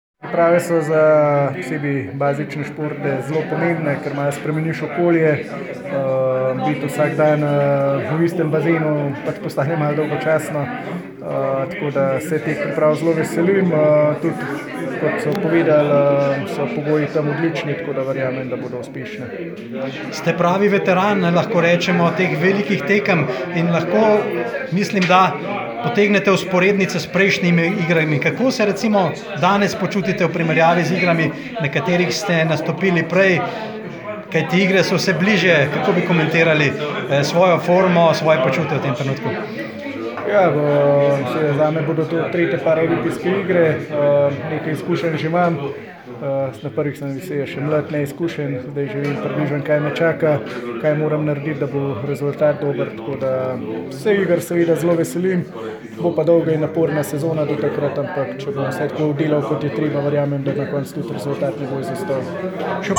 Avdio izjava